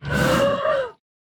Minecraft Version Minecraft Version snapshot Latest Release | Latest Snapshot snapshot / assets / minecraft / sounds / mob / panda / aggressive / aggressive2.ogg Compare With Compare With Latest Release | Latest Snapshot
aggressive2.ogg